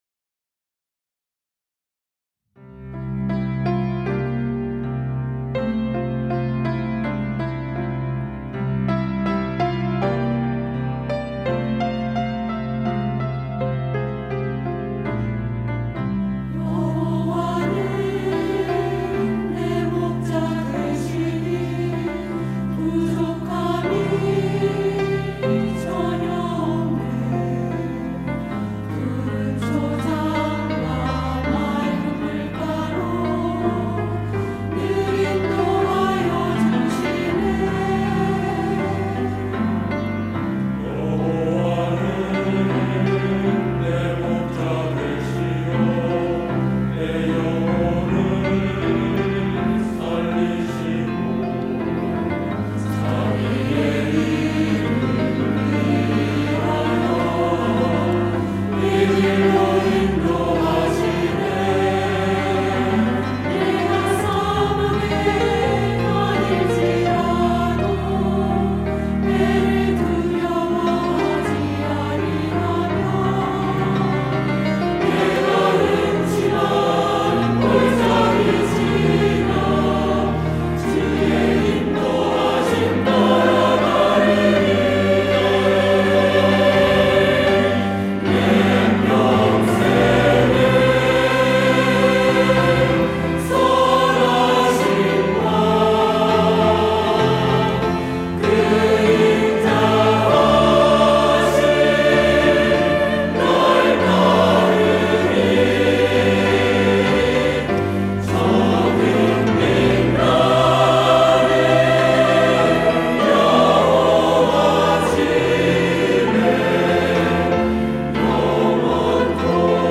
할렐루야(주일2부) - 여호와는 나의 목자
찬양대